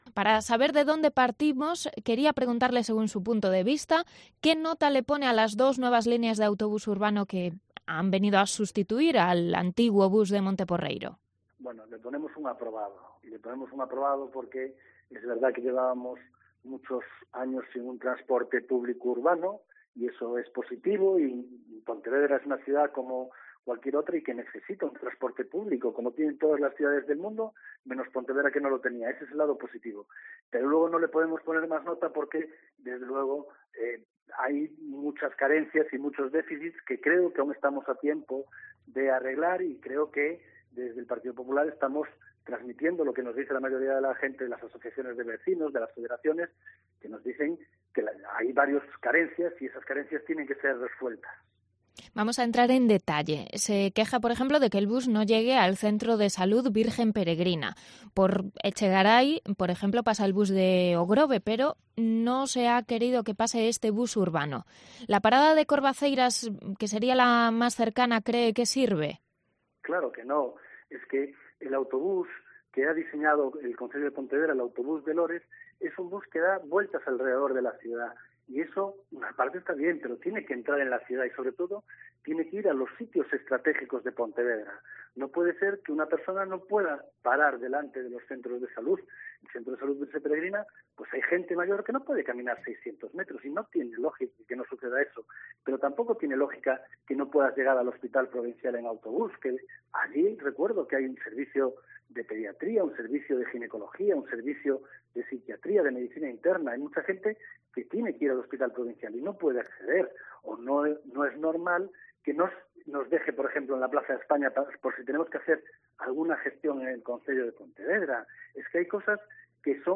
Entrevista a Rafa Domínguez, líder de la oposición de Pontevedra, sobre las nuevas líneas de bus